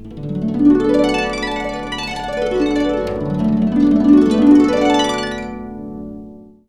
HARP ENX ARP.wav